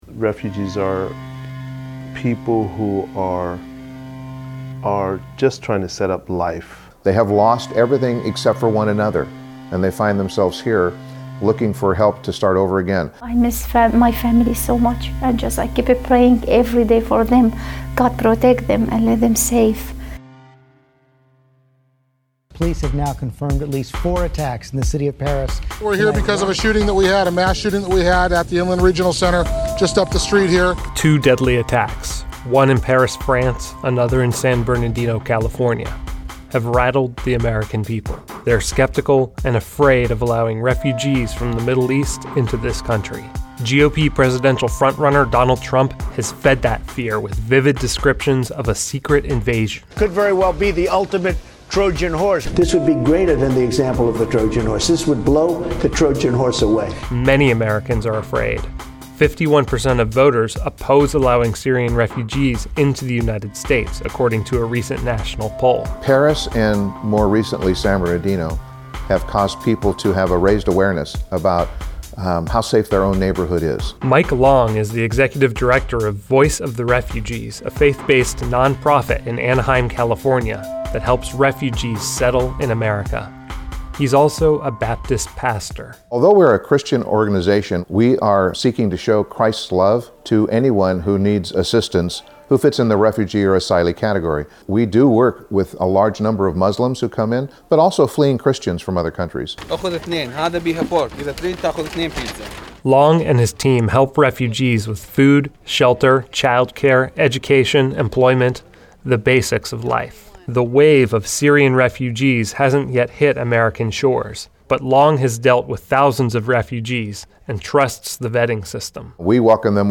Learn more about the Syrian refugees by watching the above Reason TV video, which explores the refugee settlement process through the eyes of two faith-based nonprofits, one Muslim and one Christian, that provide refugees with the basics of life, and hear the testimony of a Syrian refugee who left her war-torn country behind to start a new life with her family in America.